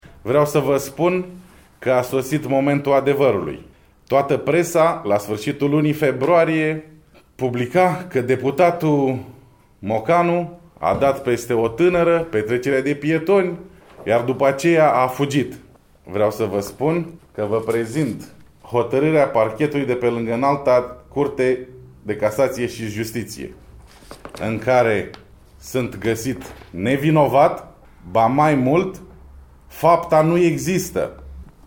Anunțul a fost făcut de Mocanu joi, în cadrul unei conferințe de presă.